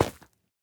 Minecraft Version Minecraft Version snapshot Latest Release | Latest Snapshot snapshot / assets / minecraft / sounds / block / fungus / break6.ogg Compare With Compare With Latest Release | Latest Snapshot